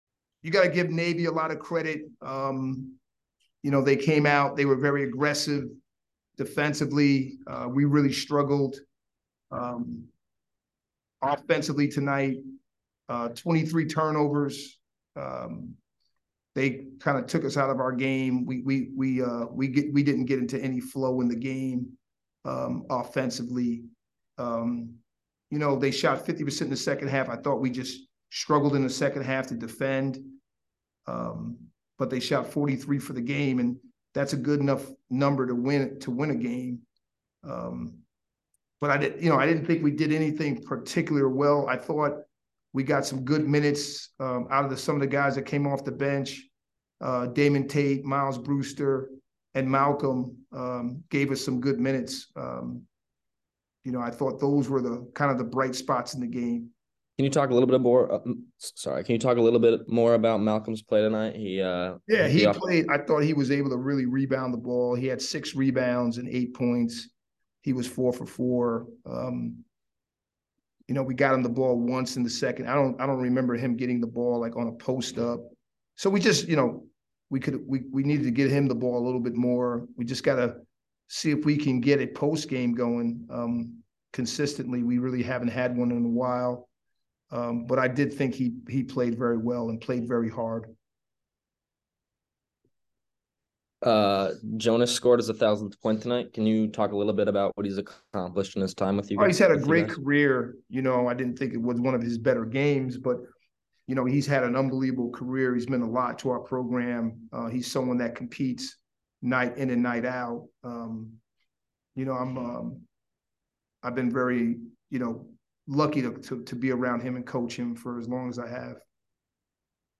Men's Basketball / Navy Postgame Interview (1-18-23) - Boston University Athletics